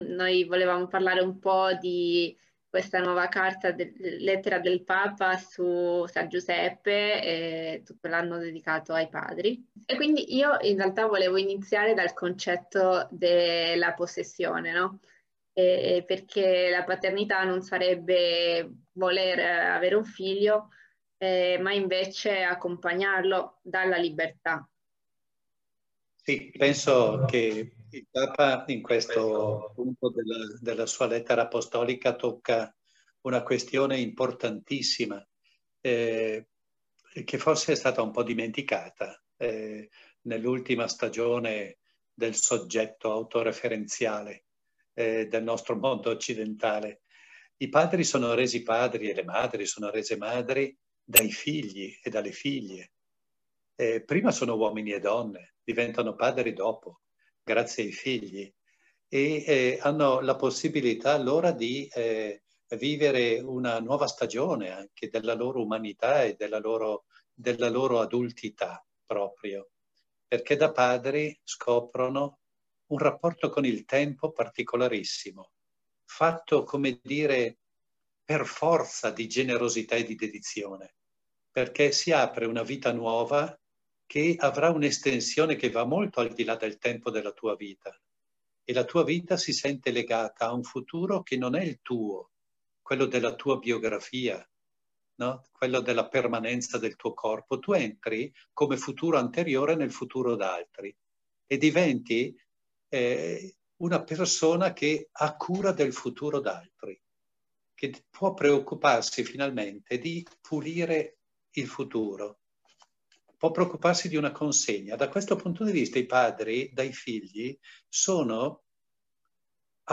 Nell’intervista